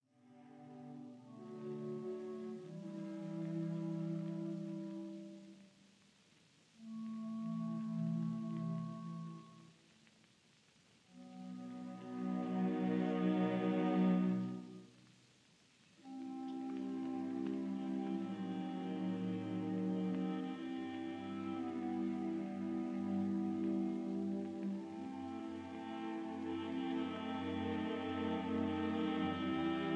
This is the first marked Andante — Allegro con anima.